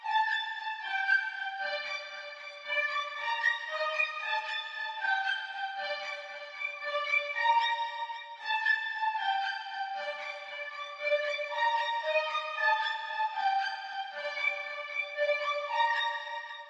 Tag: 115 bpm Trap Loops Strings Loops 2.81 MB wav Key : Unknown